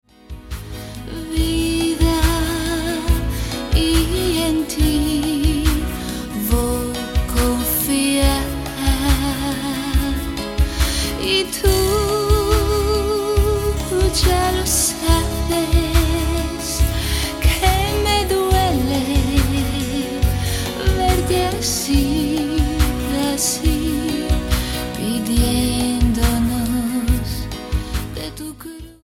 STYLE: Latin